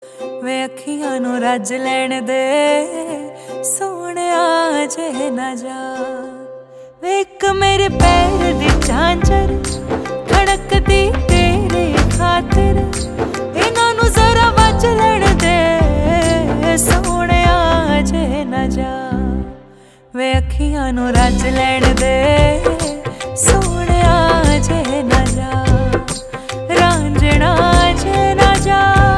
Punjabi Ringtones